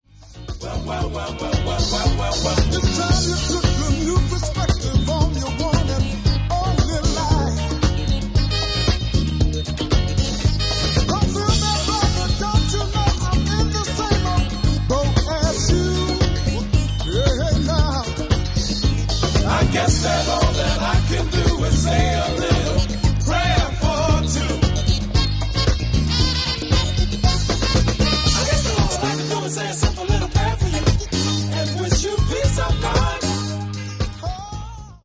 Genere:   Disco | Soul | Funk
12''Inch Remix